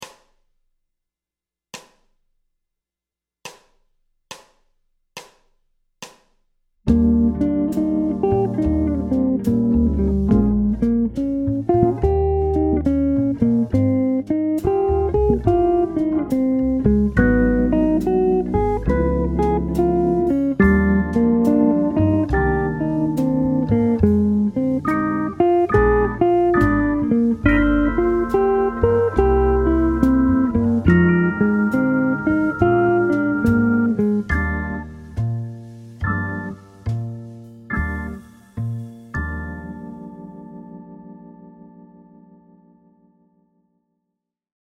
Exercice #3 : Arpèges en inversions up and down
Piste d’accompagnement pour l’exercice